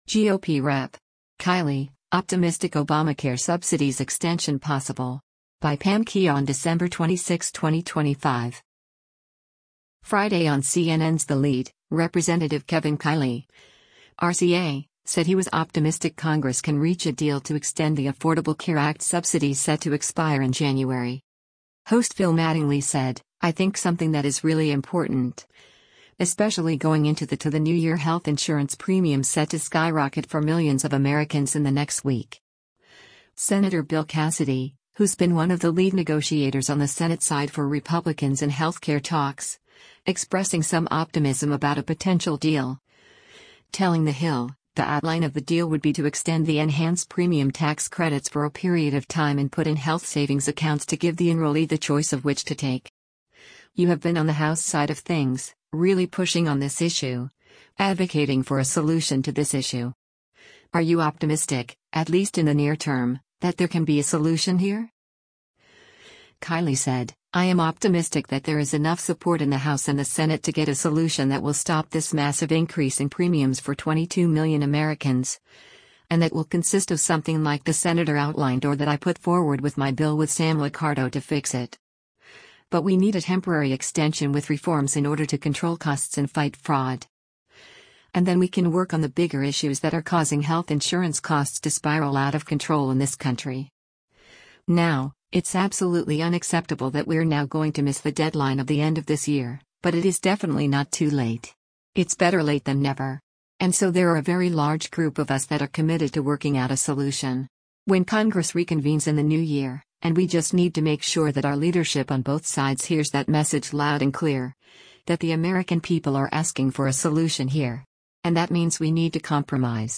Friday on CNN’s “The Lead,” Rep. Kevin Kiley (R-CA) said he was “optimistic” Congress can reach a deal to extend the Affordable Care Act subsidies set to expire in January.